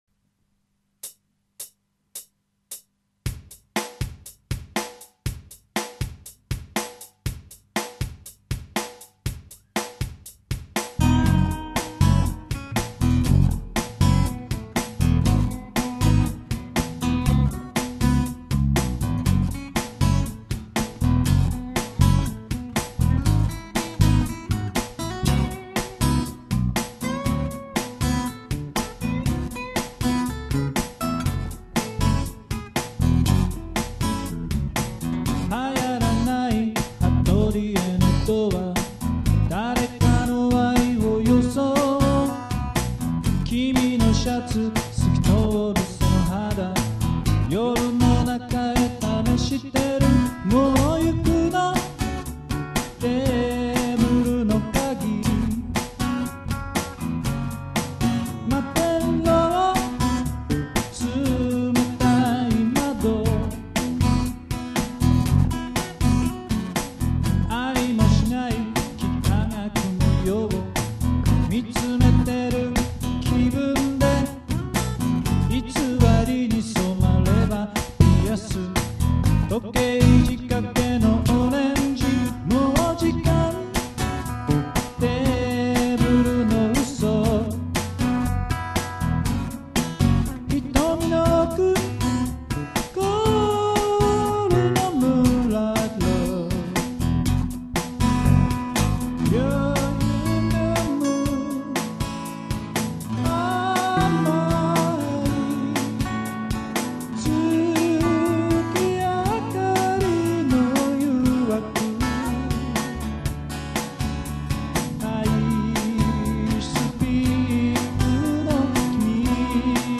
公開する音源は、短調なリズムマシンのパターンに
アコギ2本とベースをダビングしています。
いわばテストパターン的な音源です。
そもそも、自室でボソボソ歌っているだけなので、
ボーカルになってません。
ギターもベースも楽しくて、ついつい弾きすぎてしまい
若干のオーバーダブ傾向にありますね。
LOVE COLLECTION デモ アコギバージョン